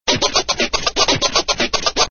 misc_fastScratch00.mp3